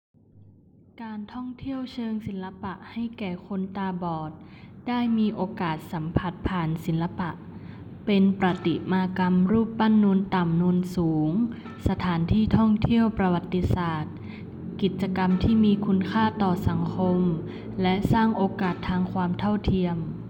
★実際にニュースを読む上げたものはこちら。